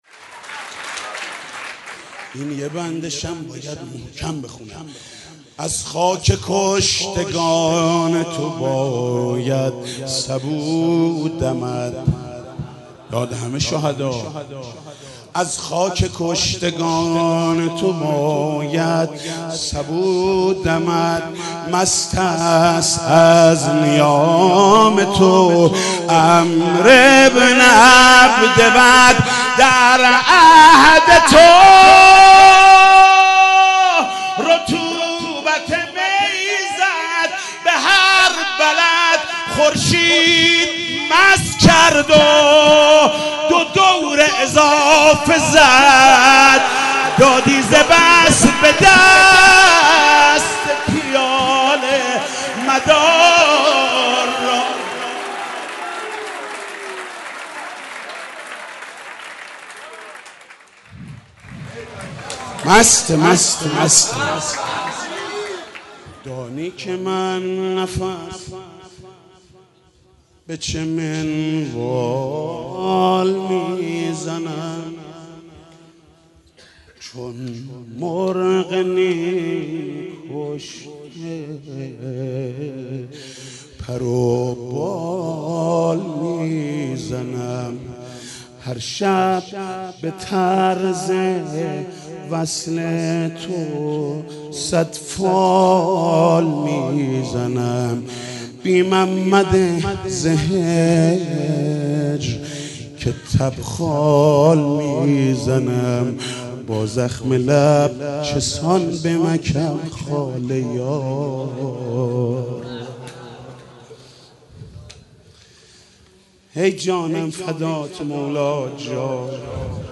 مدح: از خاک کشتگان تو باید سبو دمد